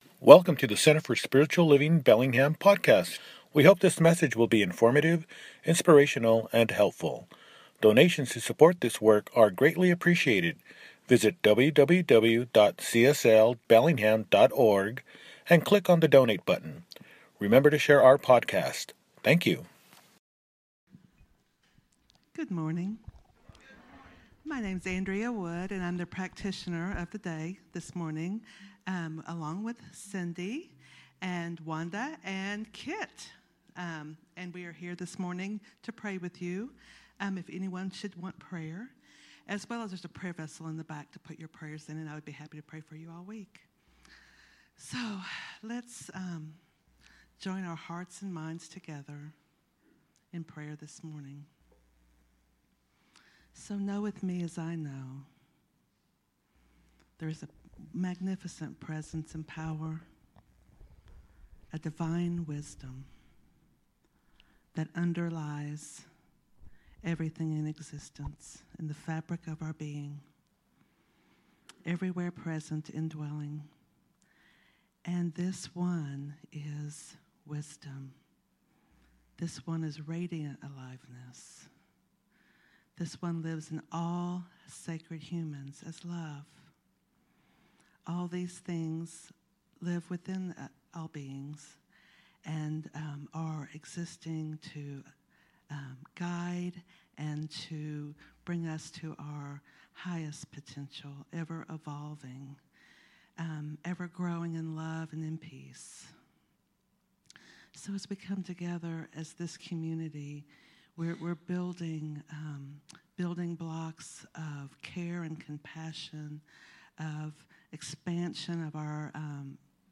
Overcoming Silence: My Voice, My Life, My Purpose – Celebration Service | Center for Spiritual Living Bellingham